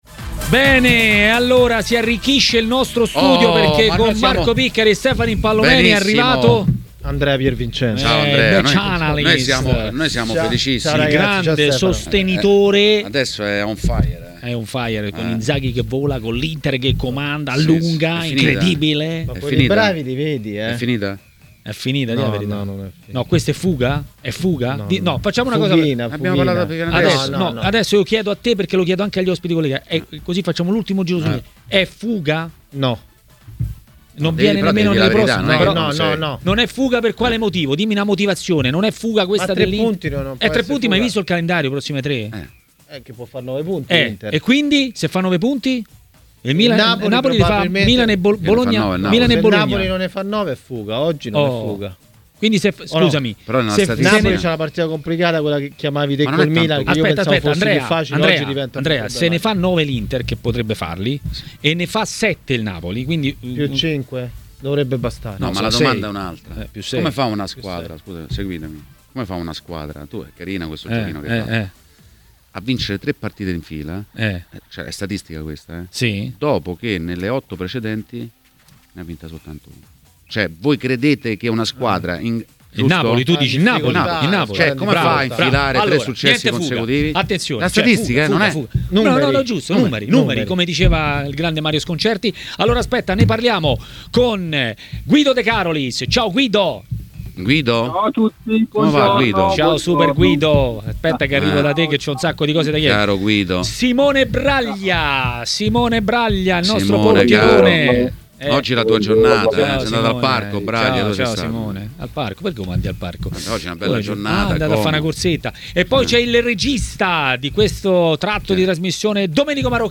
A parlare di Juventus a Maracanà, nel pomeriggio di TMW Radio, è stato l'ex calciatore Domenico Marocchino.